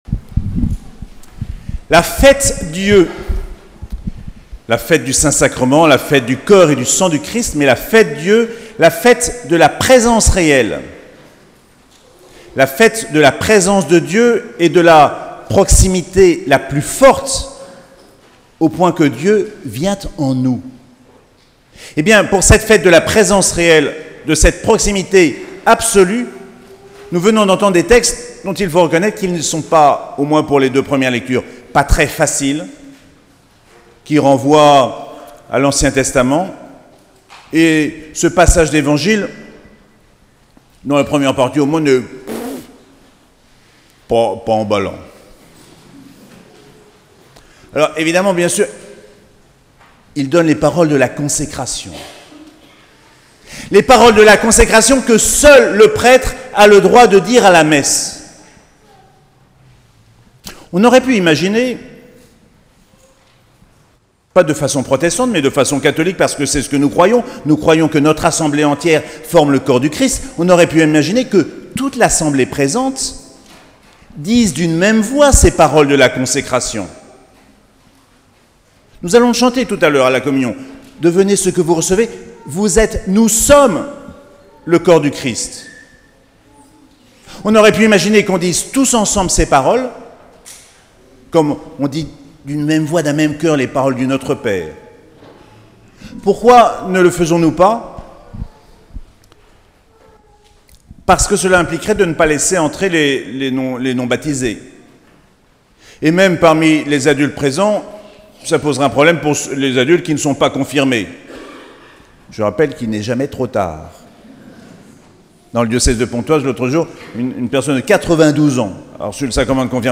Fête du Saint Sacrement - 3 juin 2018